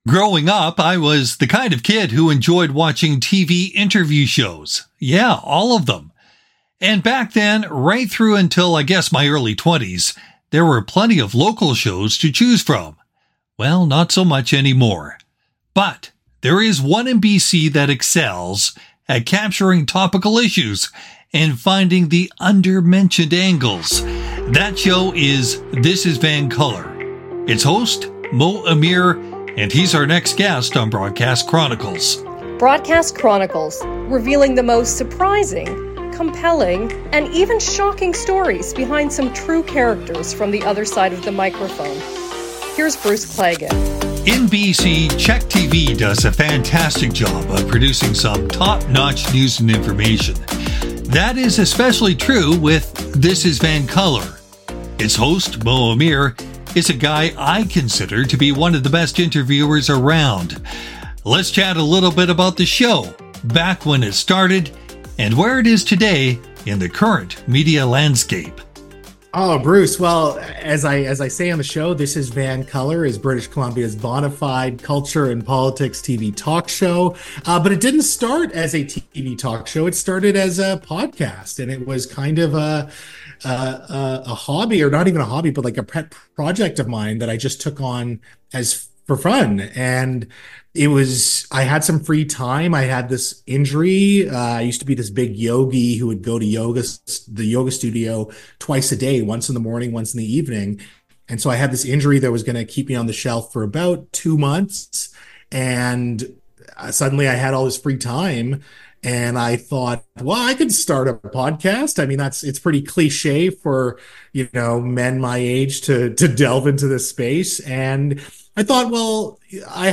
Broadcast Chronicles *Season 2 March 1, 2025*-Interview